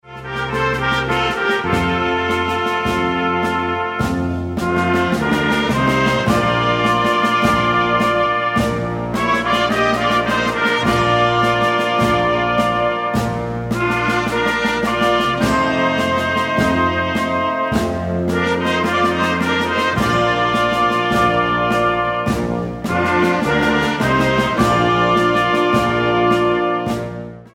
Solo für 2 Trompeten und Blasorchester Schwierigkeit
A4 Besetzung: Blasorchester Zu hören auf